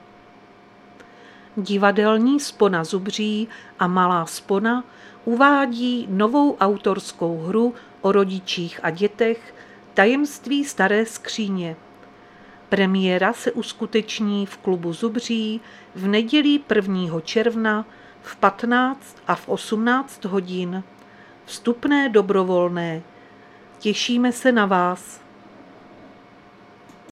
Záznam hlášení místního rozhlasu 30.5.2025